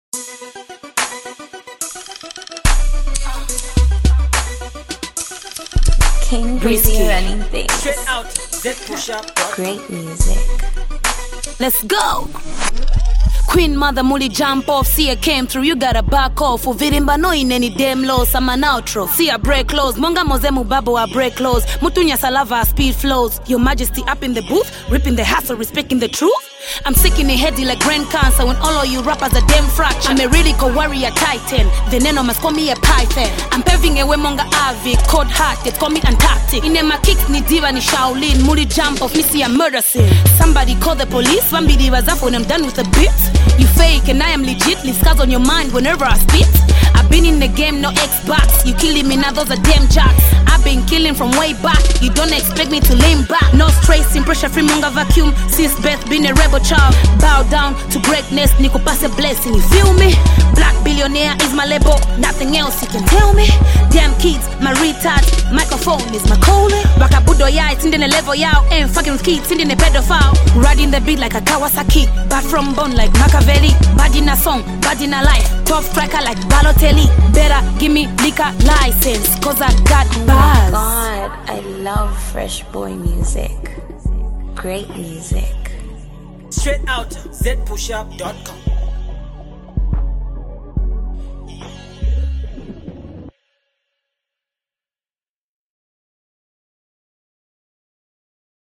on a sampled beat